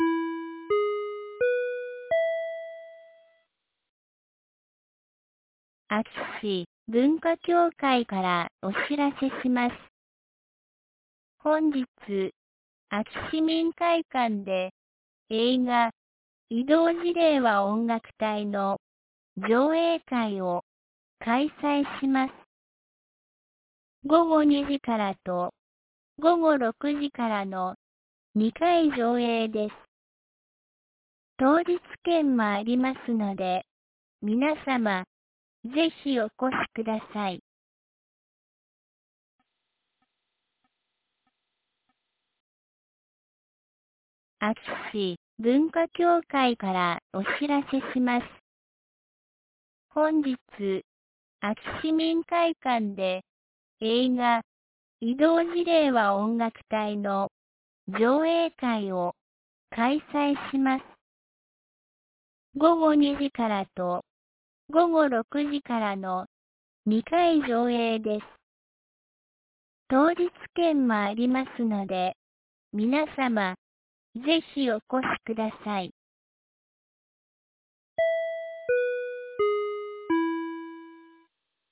2023年11月18日 12時11分に、安芸市より全地区へ放送がありました。